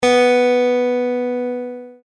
Use the audio tones below to tune your guitar to an Open E Tuning (commonly used for playing slide).
B String